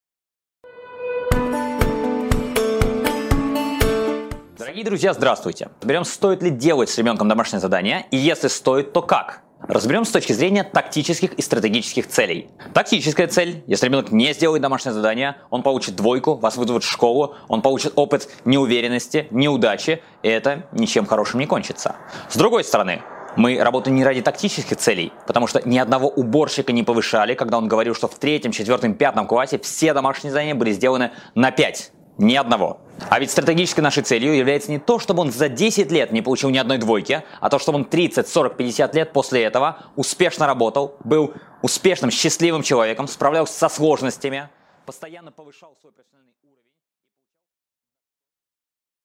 Аудиокнига Нужно ли делать домашнее задание с ребенком?